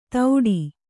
♪ tauḍi